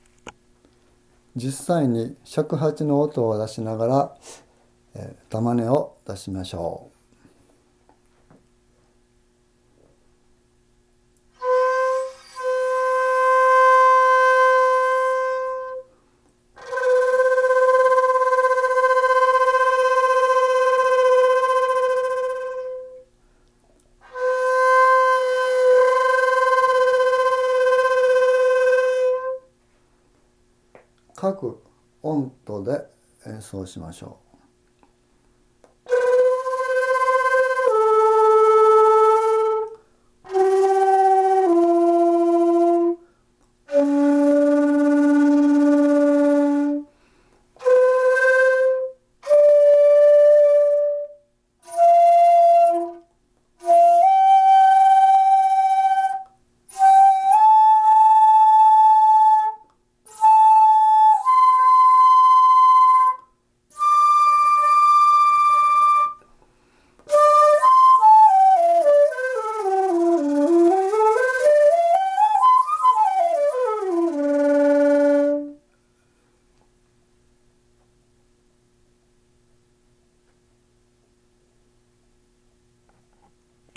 次に、実際に尺八の音を出しながら玉音にします。
すべての音を玉音にします。
リチレツロ（乙）ロツレチリロ（甲）ツレチヒハ五